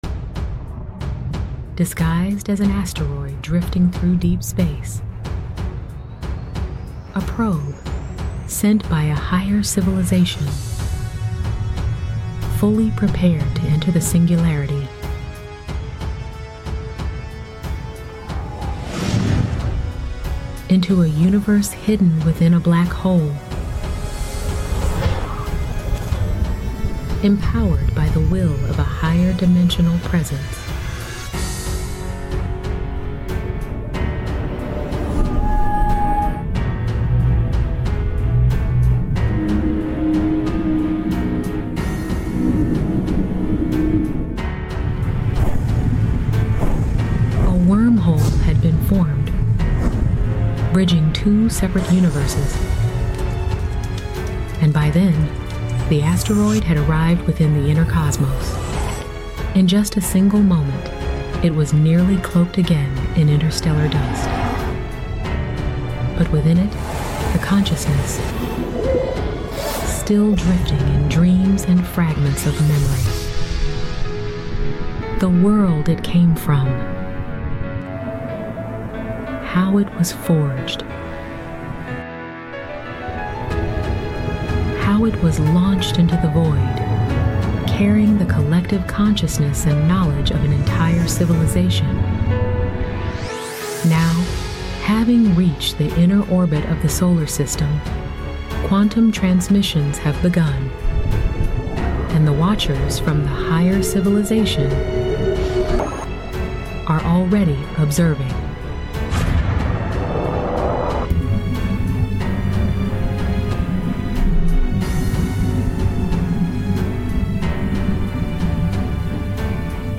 Here’s a narrated version of sound effects free download